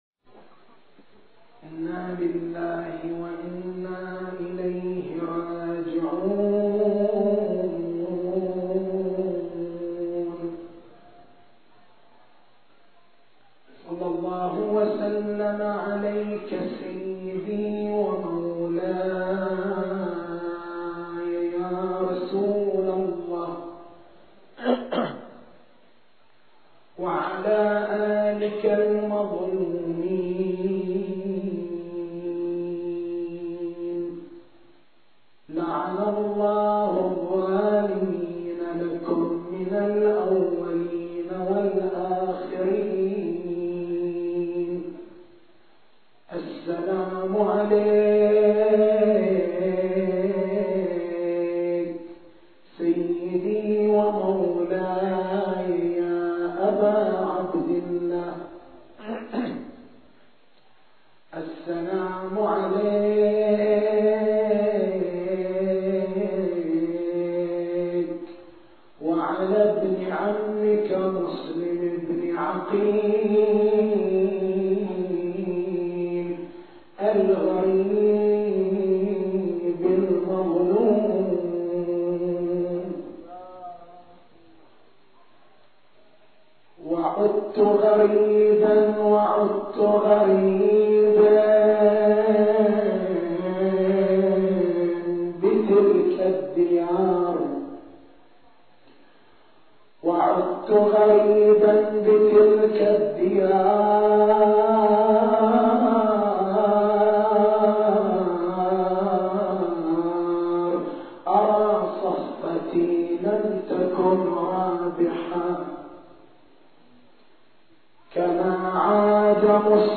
تاريخ المحاضرة: 04/01/1425 نقاط البحث: لماذا لا تتم غربلة المجاميع الروائية الشيعية لتنزيهها عن الأحاديث الموضوعة؟ لماذا لا يوجد عند الشيعة كتاب صحيح؟